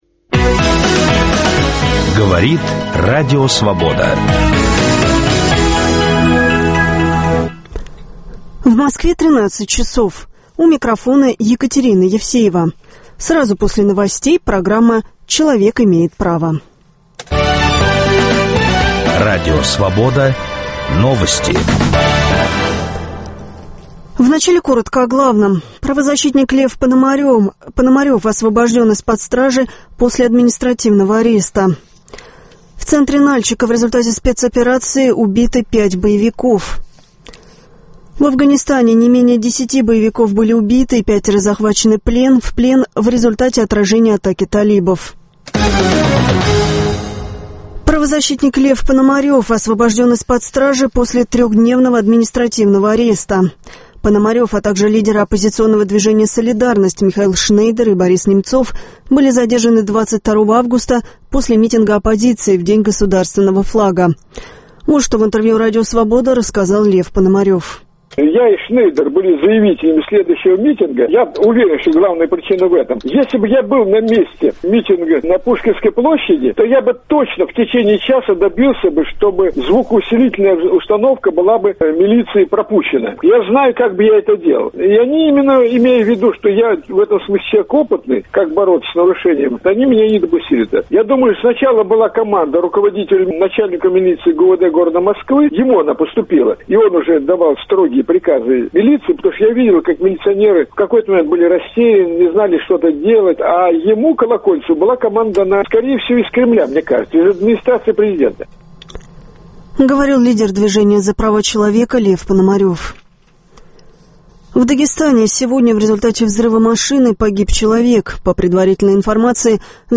В студии президент Адвокатской палаты Москвы Генри Резник.